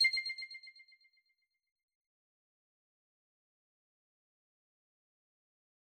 back_style_4_echo_007.wav